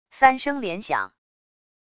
三声连响wav音频